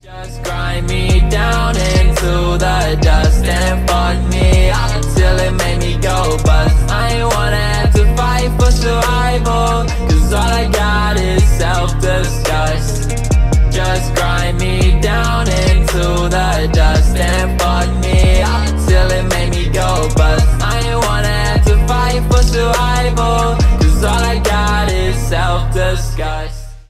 Рэп и Хип Хоп # Электроника